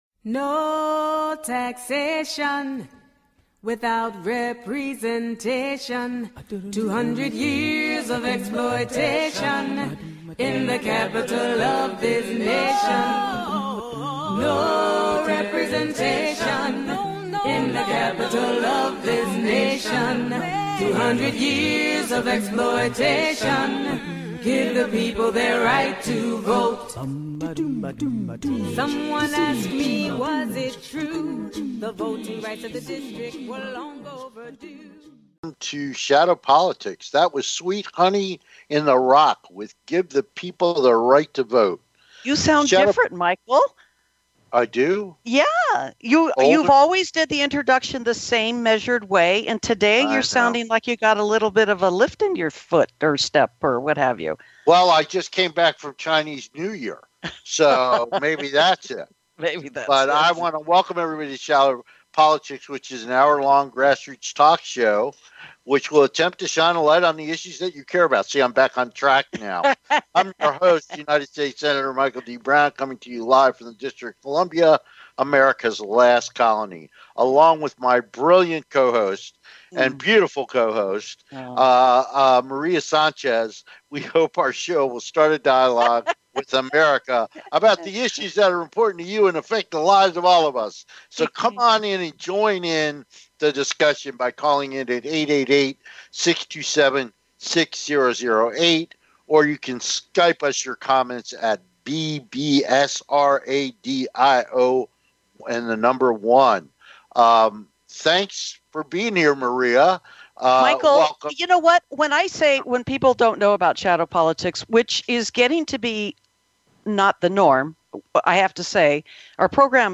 Shadow Politics is a grass roots talk show giving a voice to the voiceless. For more than 200 years the people of the Nation's Capital have ironically been excluded from the national political conversation.
We look forward to having you be part of the discussion so call in and join the conversation.